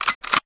bandit_reload_01.wav